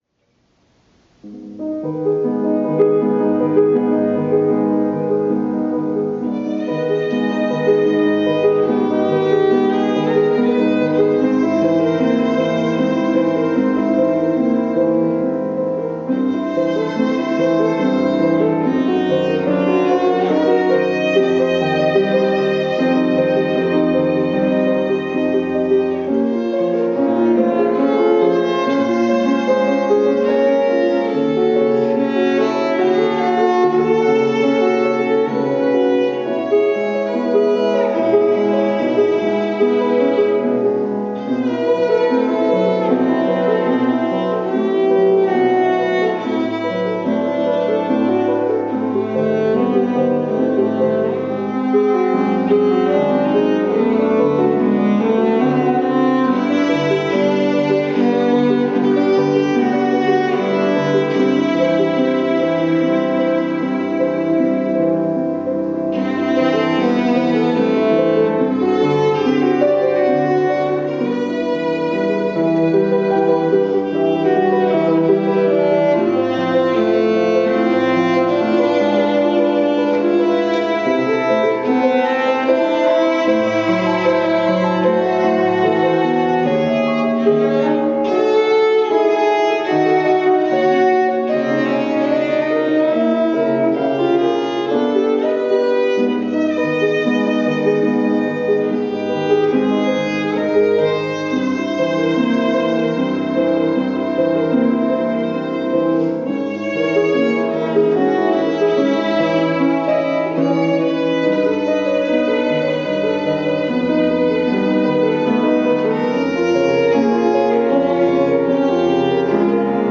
Cello Recital - Two Swans
I performed two pieces in my cello teacher's studio recital.
of Lidström Swan (with piano accompaniment)